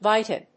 /ˈvaɪtʌ(米国英語)/